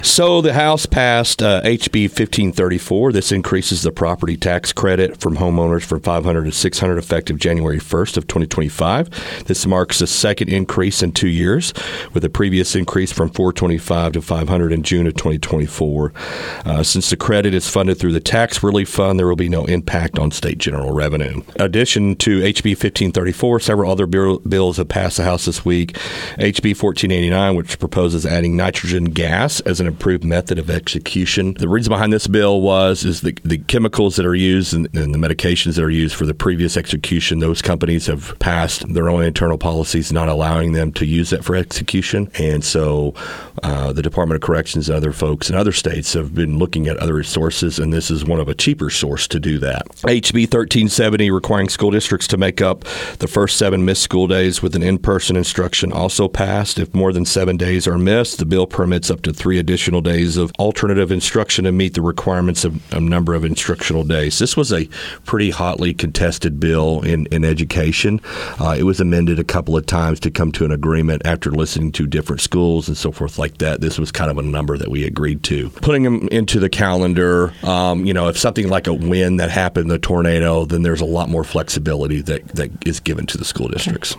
District 3 Arkansas State Representative Stetson Painter spoke with KTLO, Classic Hits and The Boot News and shares some of the bills the House passed this week.